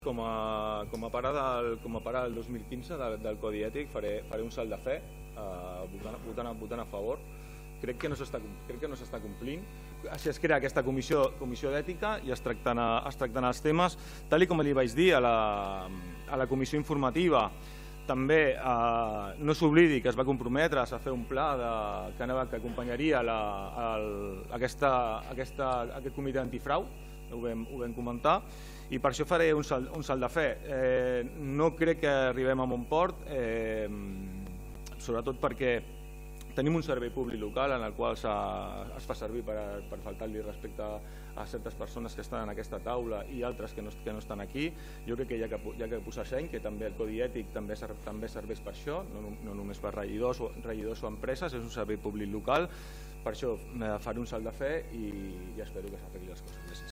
El regidor no adscrit, Albert Sales, que també va aportar propostes, va votar a favor “fent un salt de fe” tot i considerar que ara no s’estava complint aquest codi ètic: